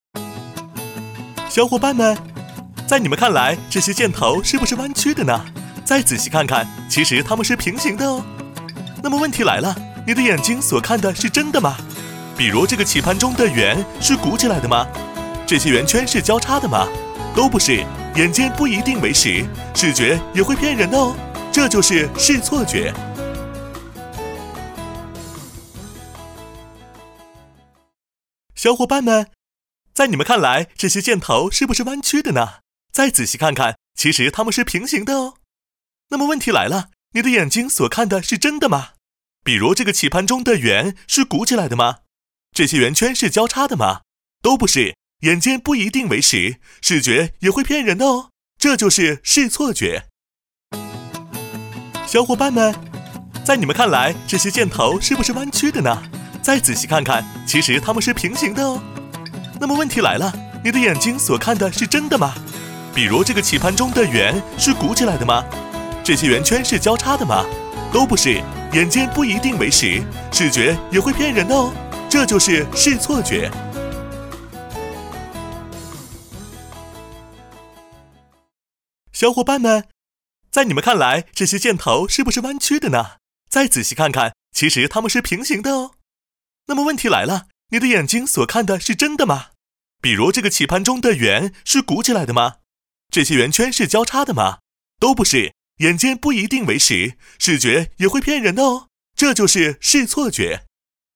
• 男S310 国语 男声 飞碟说【飞碟病毒MG】 积极向上|时尚活力|脱口秀